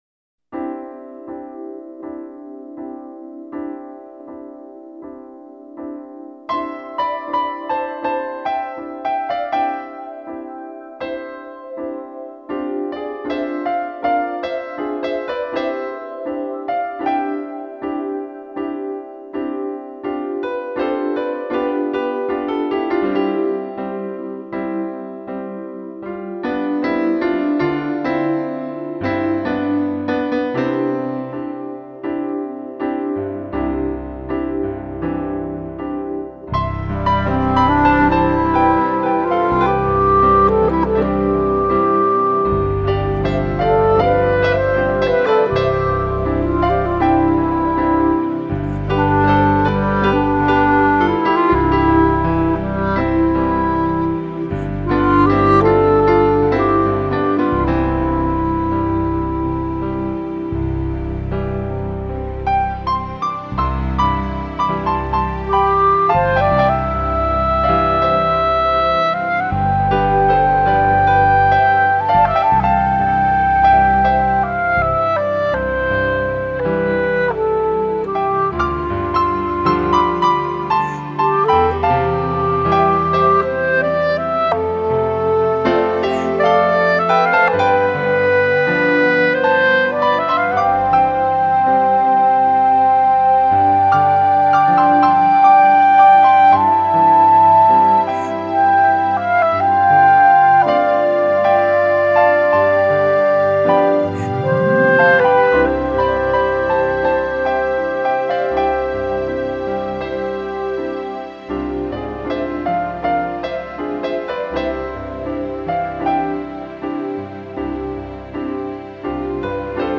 、吉他、雙簧管、單簧管、二胡........